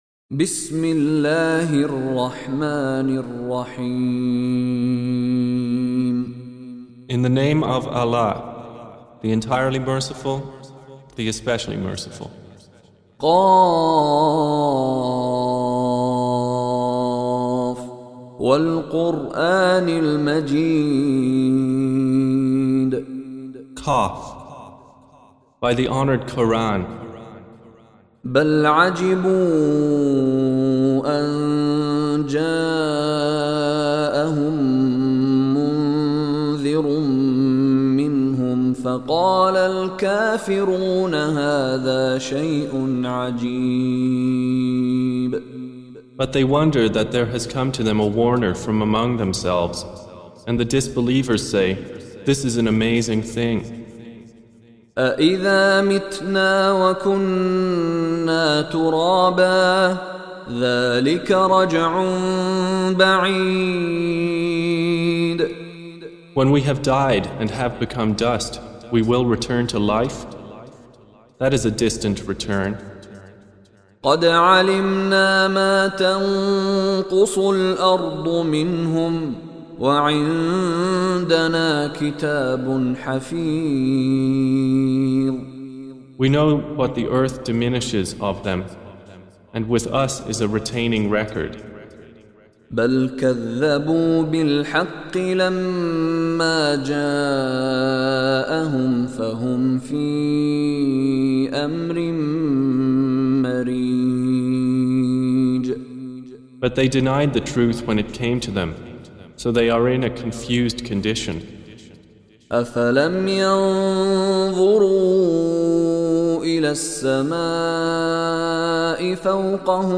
Audio Quran Tarjuman Translation Recitation